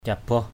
/ca-bɔh/ 1.